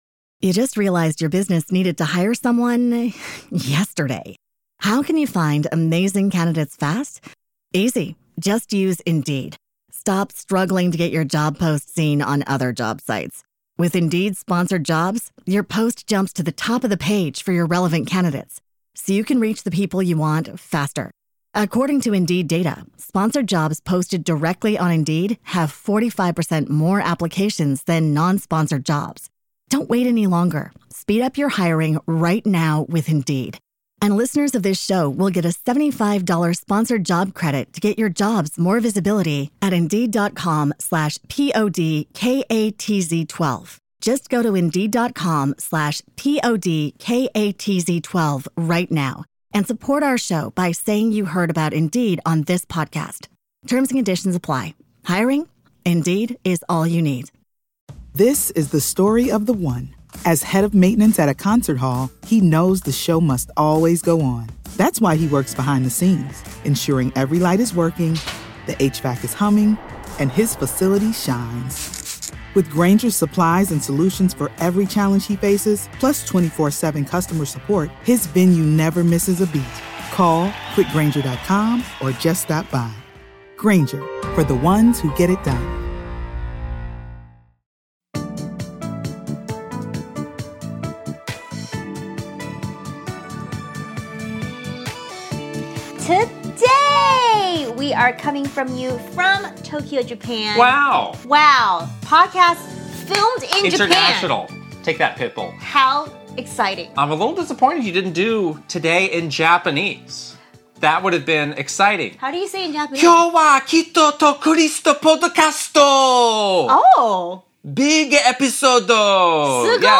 We're coming at you from Tokyo, Japan! We're about halfway through our trip and we want to tell you all about it.